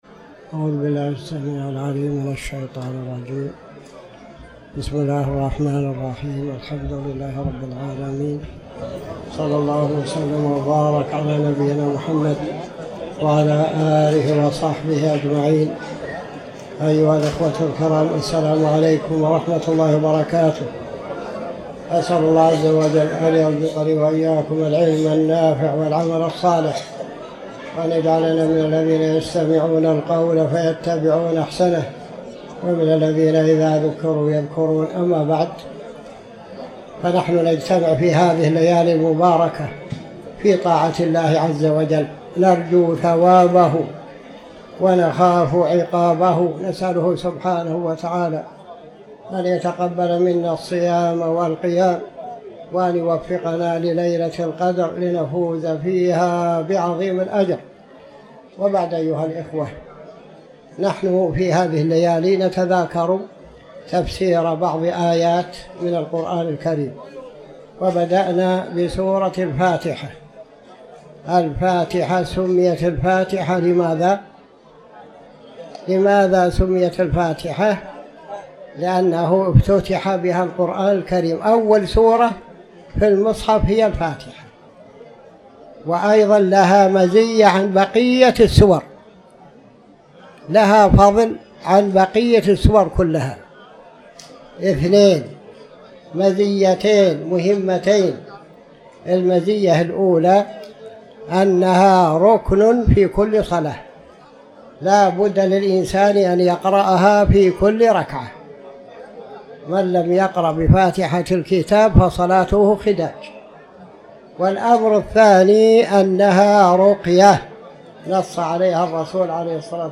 تاريخ النشر ٢٢ رمضان ١٤٤٠ هـ المكان: المسجد الحرام الشيخ